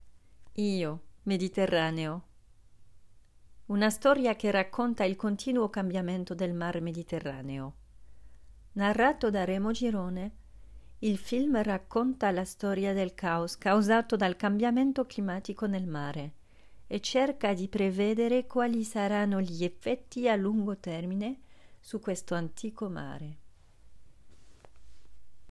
Io, Mediterraneo (voix off italien)
16 - 40 ans - Mezzo-soprano